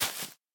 Minecraft Version Minecraft Version latest Latest Release | Latest Snapshot latest / assets / minecraft / sounds / block / sponge / step3.ogg Compare With Compare With Latest Release | Latest Snapshot
step3.ogg